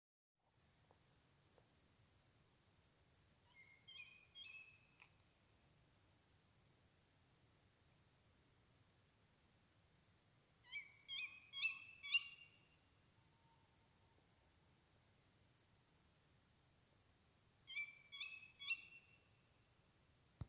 Birds -> Owls ->
Tawny Owl, Strix aluco
StatusPair observed in suitable nesting habitat in breeding season